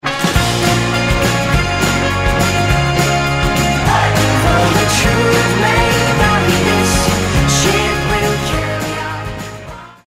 GenreFolkpop
handgemacht, beschwingt und mitreissend.